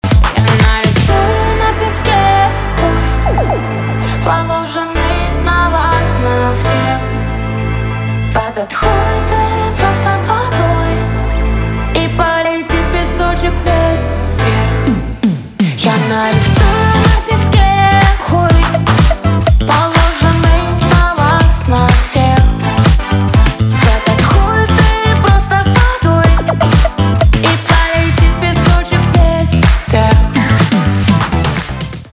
поп
пианино
диско